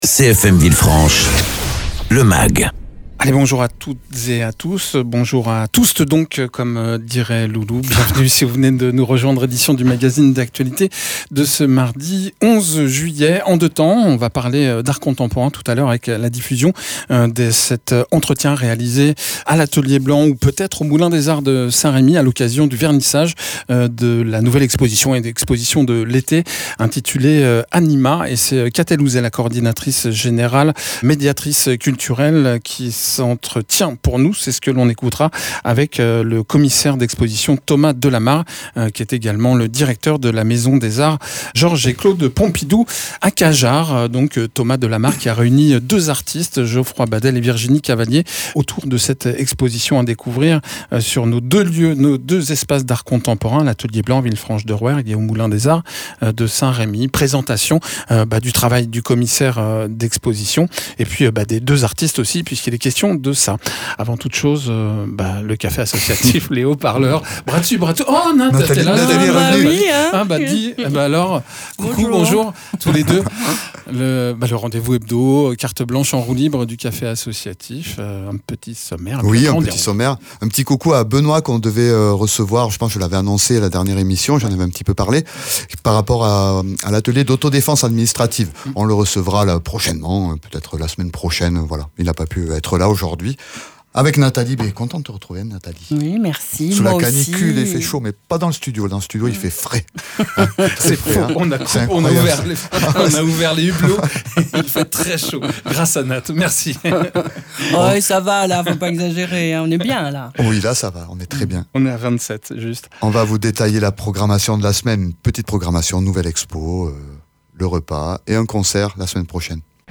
Le programme des animations de la semaine et à venir aux Hauts Parleurs, 34 rue Alibert. Egalement dans ce mag, reportage consacré à l’exposition « Anima » actuellement visible et jusqu’au 3 septembre à l’Atelier Blanc et au Moulin des arts de St Rémy.
Mags